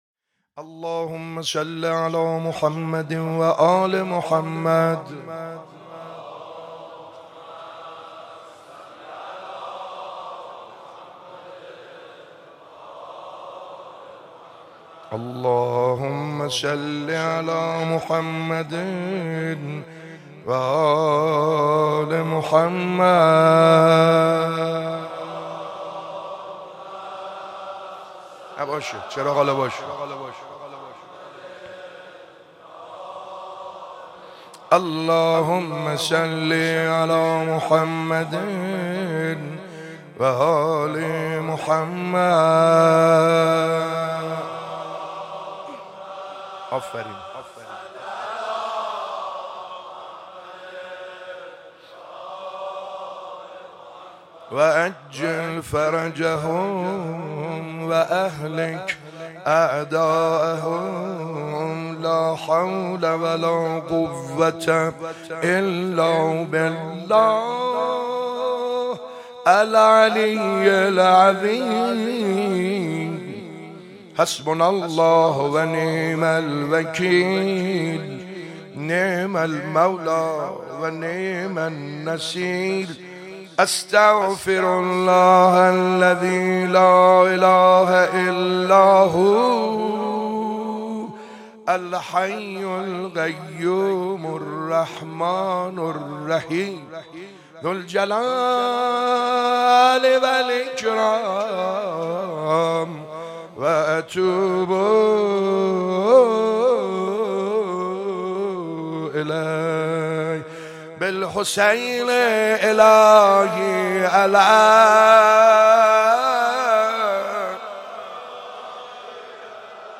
شب اول محرم94-روضه-ای آل سکوت ترک تازی مکنید